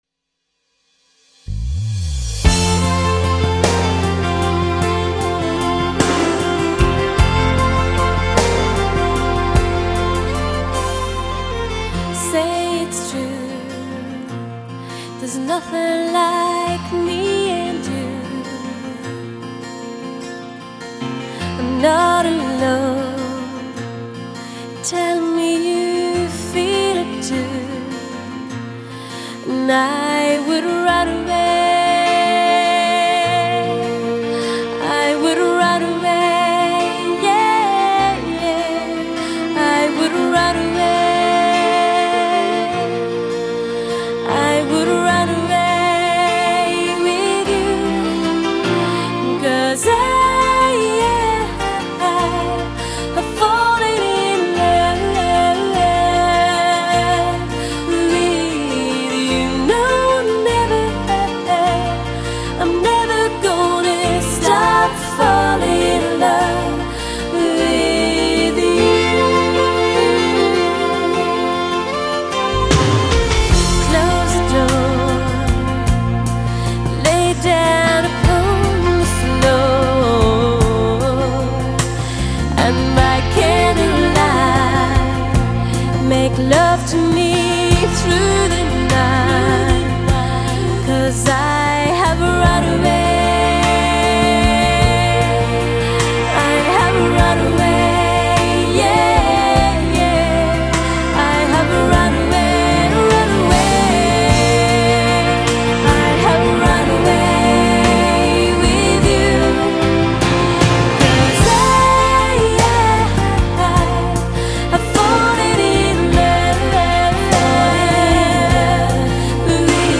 吉他/键盘
小提琴
主唱/锡笛)所组成。